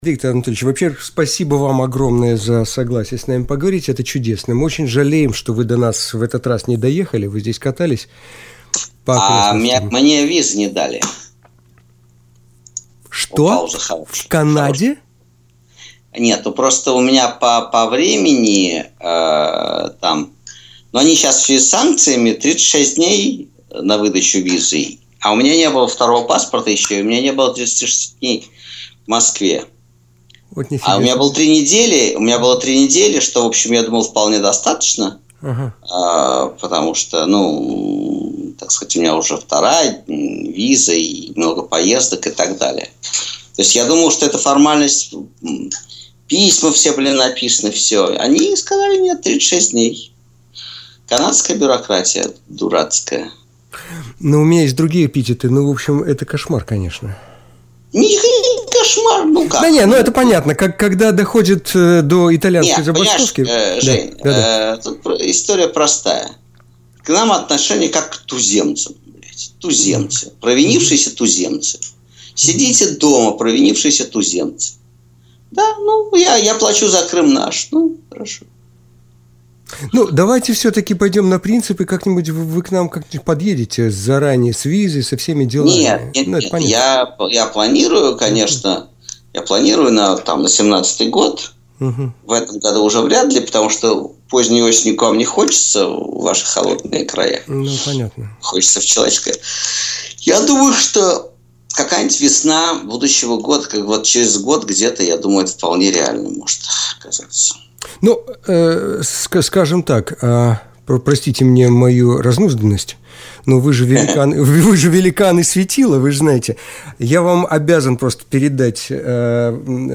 Виктор Шендерович – Запись эфира “Радио Плюс”